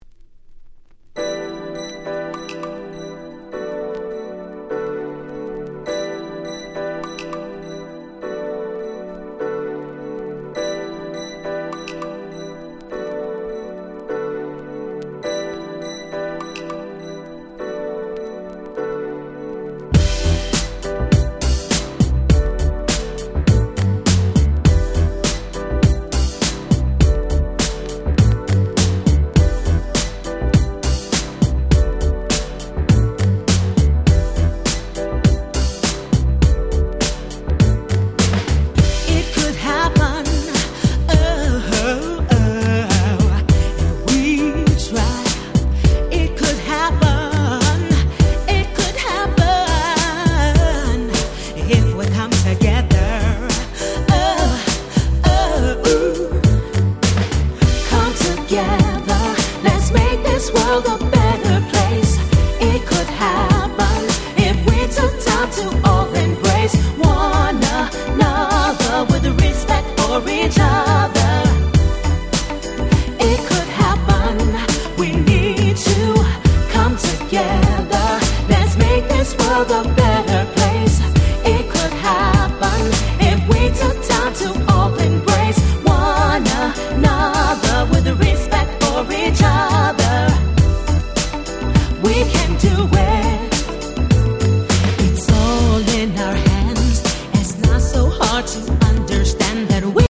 95’ Super Nice R&B !!